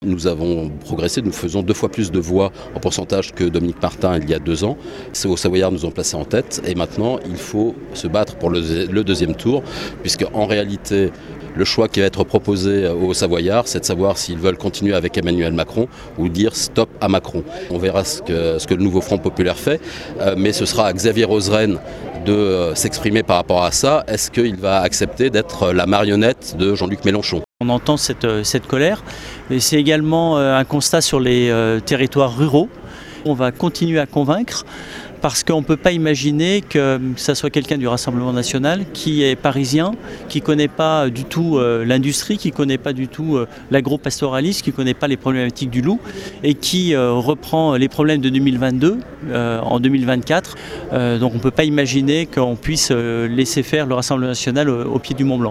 Charles Prats et Xavier Roseren à notre micro :